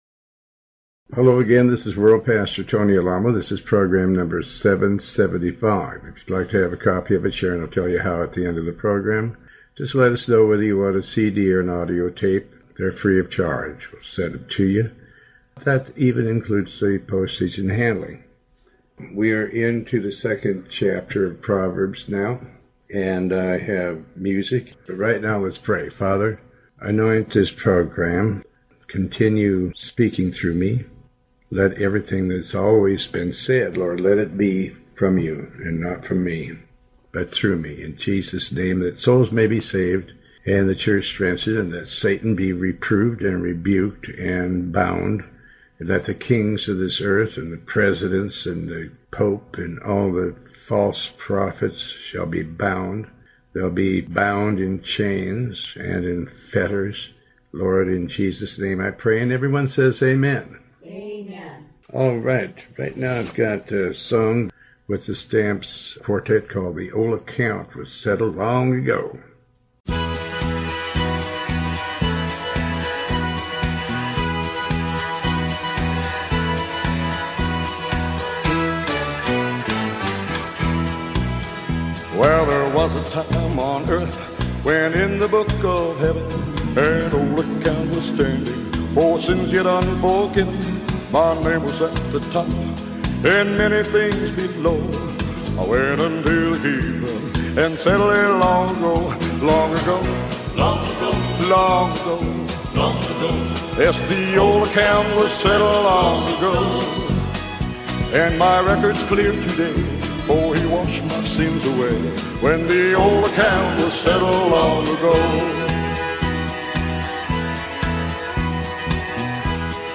In this program originally recorded in 2008, Pastor Alamo reads from and comments on the Book of Proverbs, chapter 2 verse 7 through chapter 4 verse 8. The program also contains letters and some music.